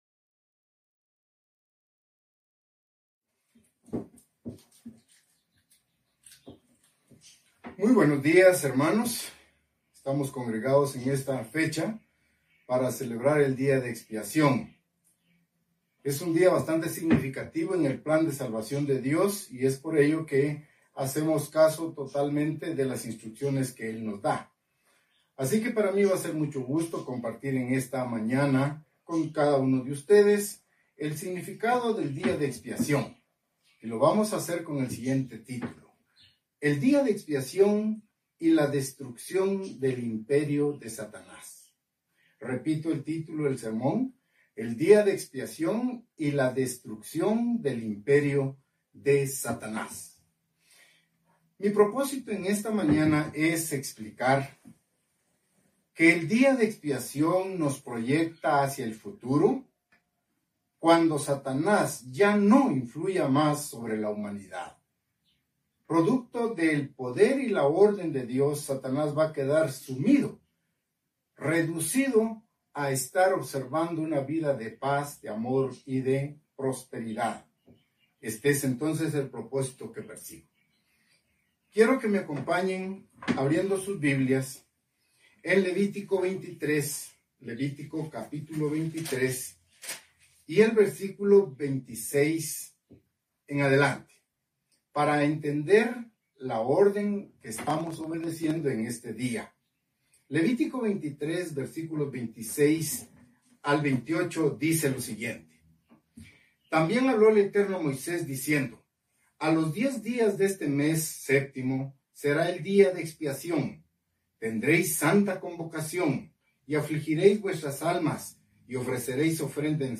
Given in Ciudad de Guatemala